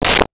Whip 02
Whip 02.wav